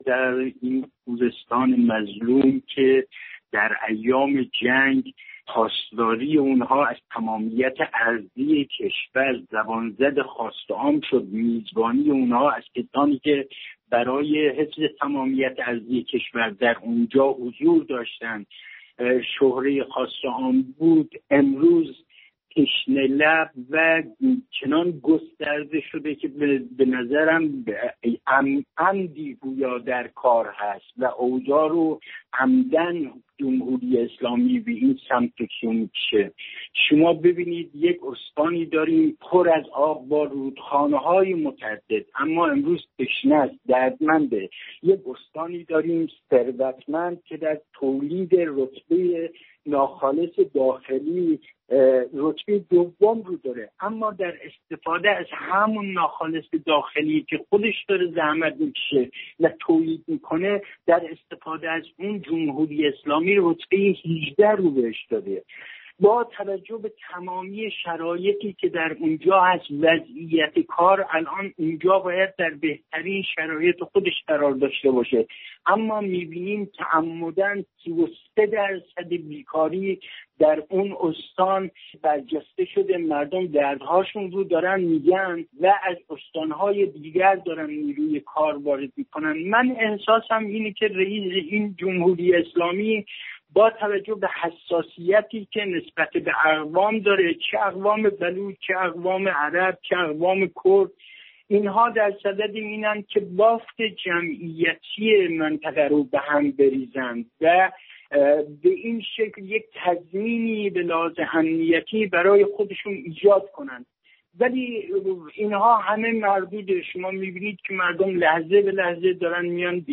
در گفتگو